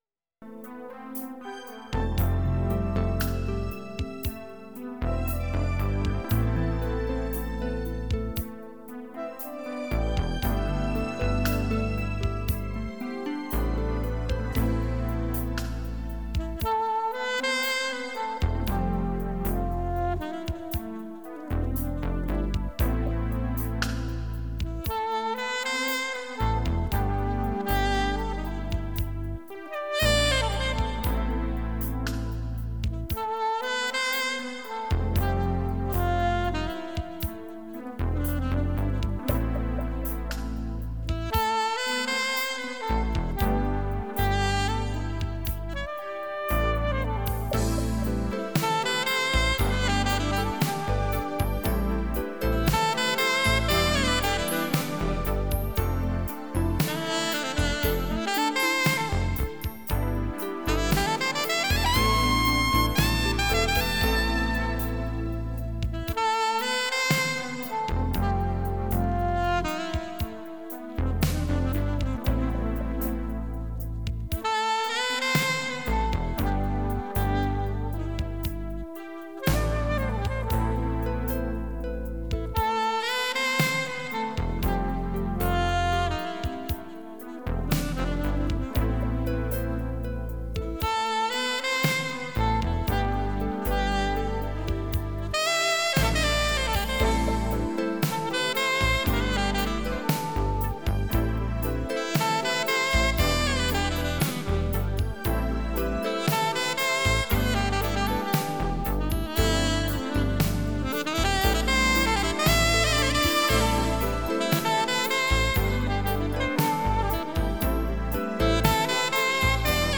Genre: Instrumental Pop.